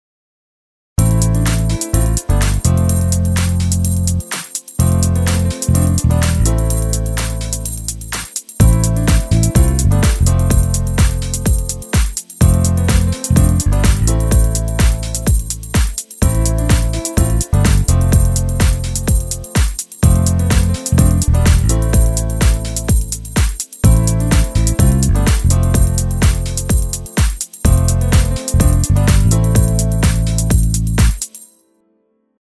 התחלתי לאחרונה ללמוד על קיובייס מעניין אותי לשמוע הערות והארות (עדיף לשמוע עם אוזניות) טראק על קיובייס 1.mp3 (דרך אגב רוב הפלאגינים שהורדתי זה מהפורום אז תודה…)